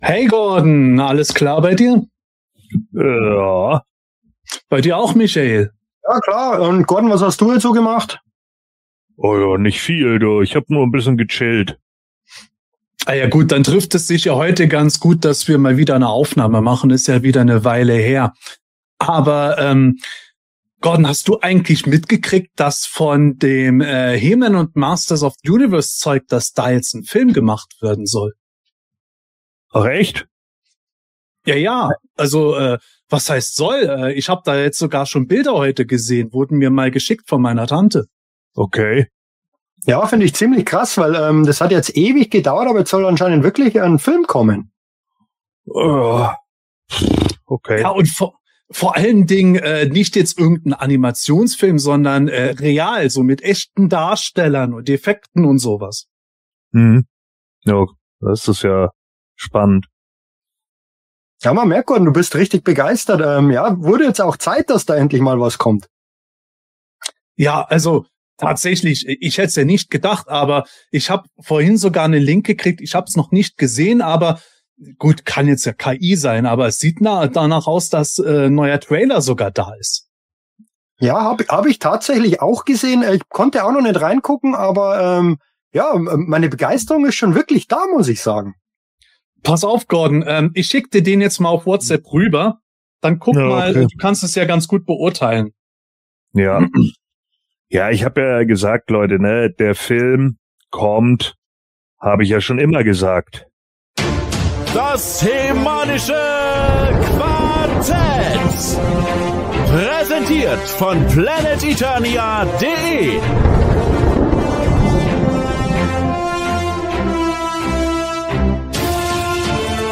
Was sagen die Männer vom HE-MANischen Quartett dazu?